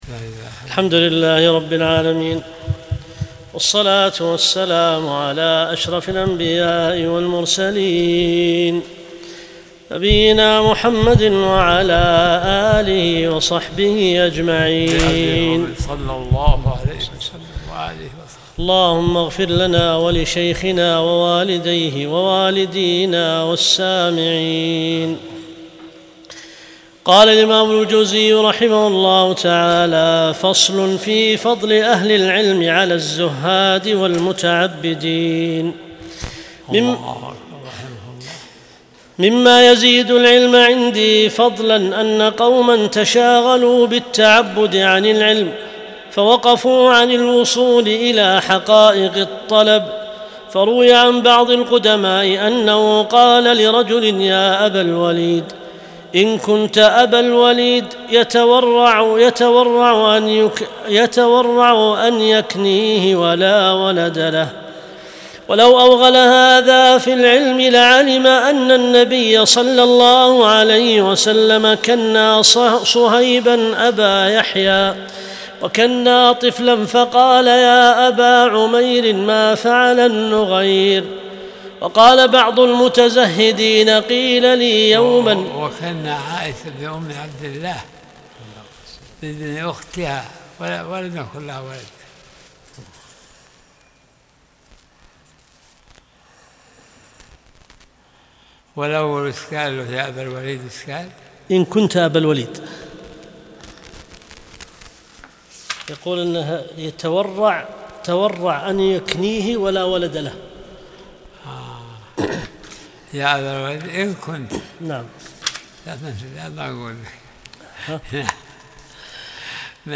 درس الأربعاء 36